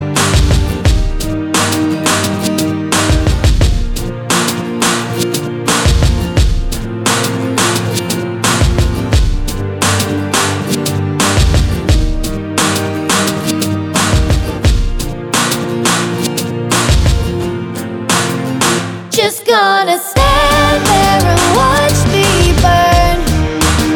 For Solo Male Pop (2010s) 4:21 Buy £1.50